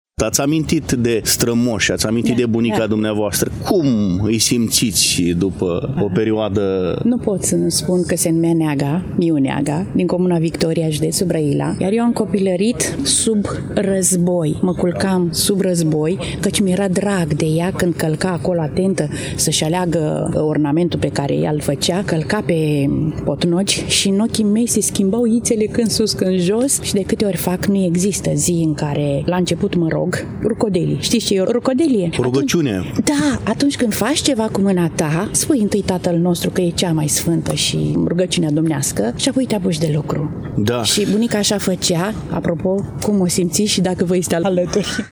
Interviul integral îl puteți asculta diseară pe undele Radio Iași, în emisiunea Tradiții, începând cu ora 21 și 30 de minute.